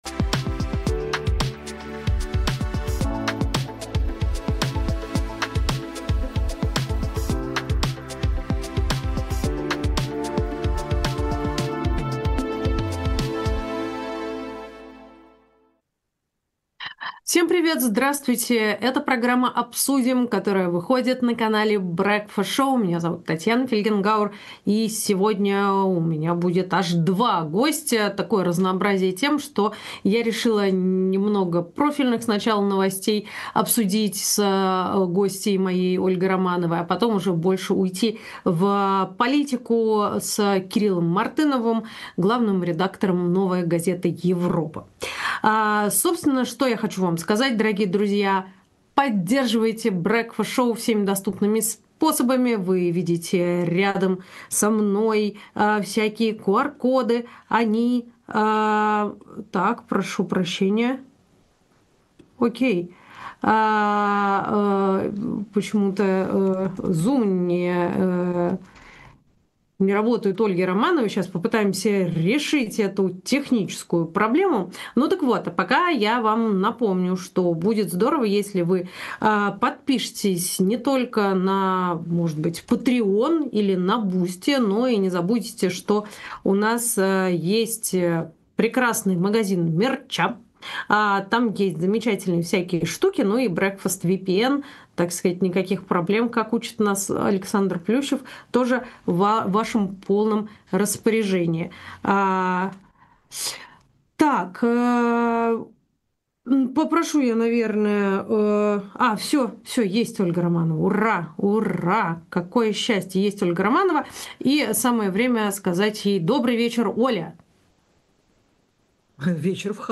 Эфир ведёт Татьяна Фельгенгауэр